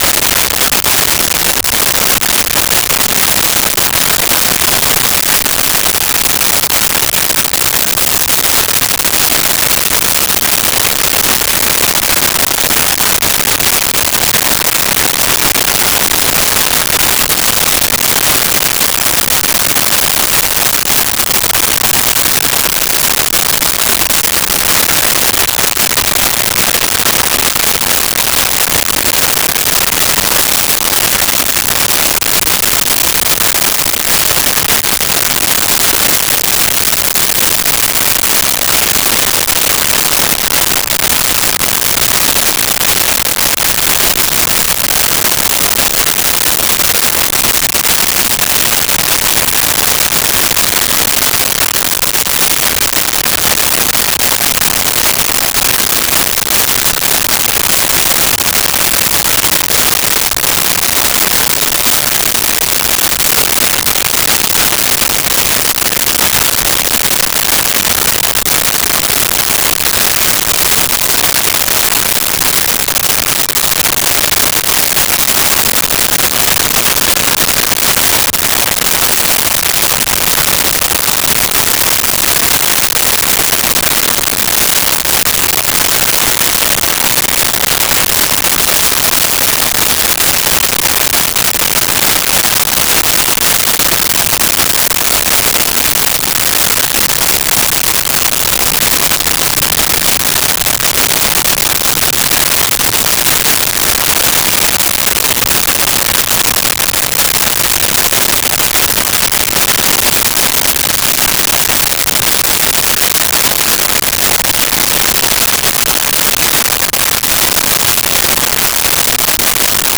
Sprinkler System
Sprinkler System.wav